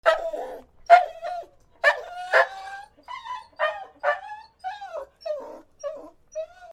/ D｜動物 / D-15 ｜犬
犬の鳴き声
『アオーン』